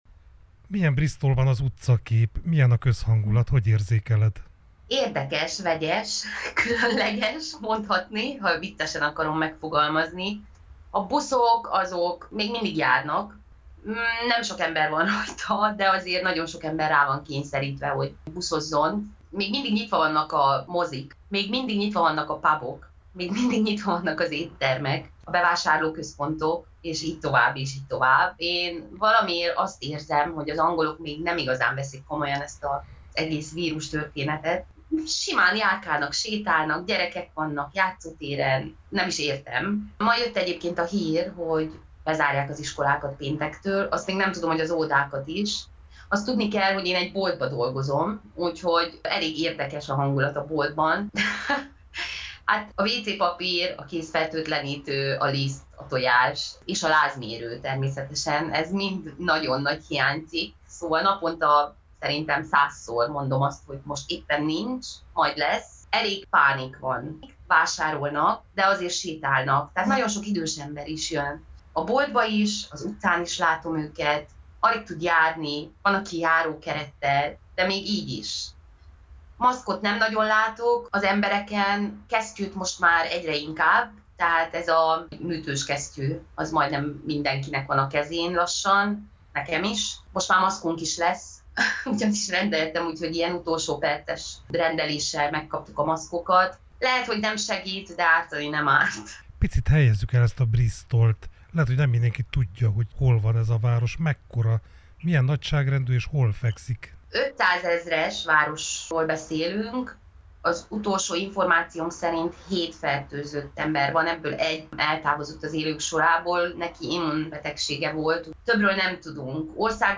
interjúját